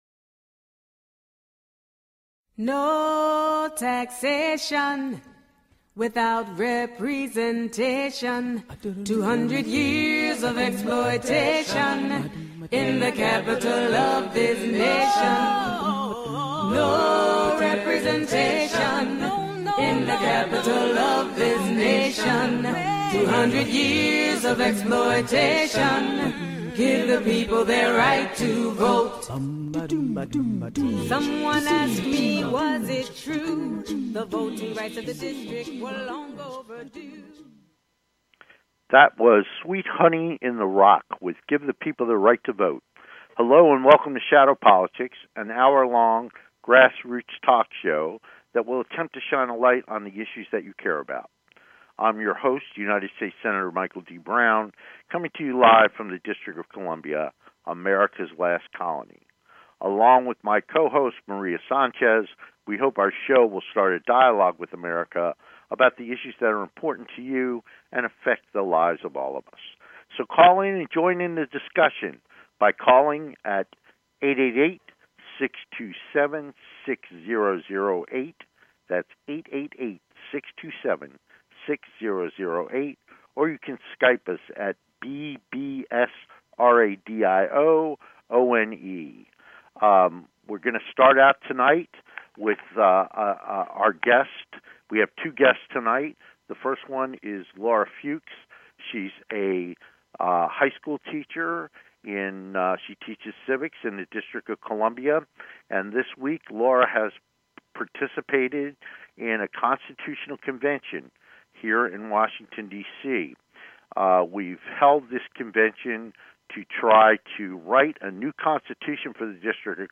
Talk Show Episode, Audio Podcast
Shadow Politics is a grass roots talk show giving a voice to the voiceless.